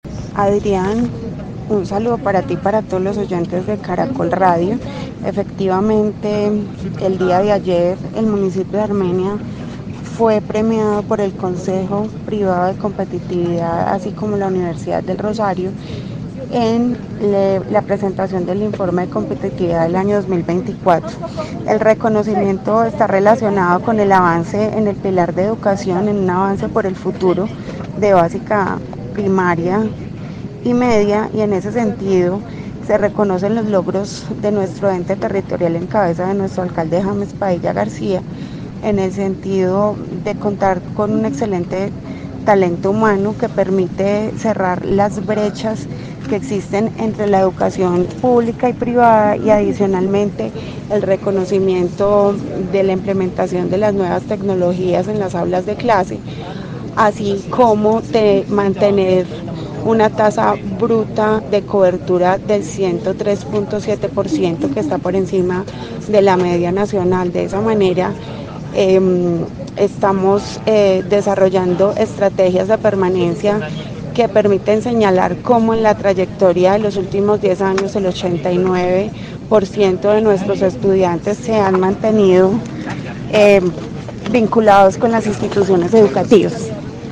Paula Andrea Huertas, secretaria de educación de Armenia